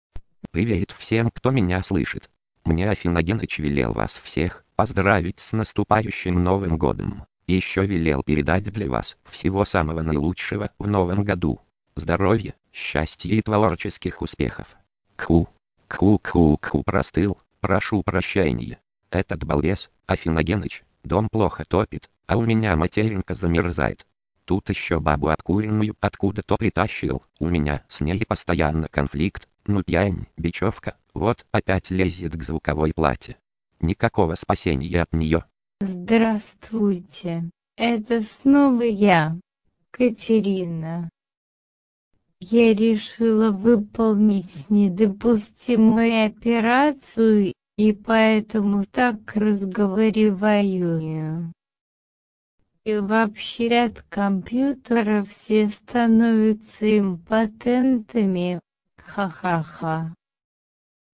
Читает, конечно, занудливо, но со временем привыкаешь.
Единственно плохо, если вечер, так этот монотонный голос быстро убаюкивает.
Голос «Катерина», баба в стельку!
А здесь я вставлю аудиофайл, где я своей говорилке текст написал, а следом за ним эта самая «Катерина»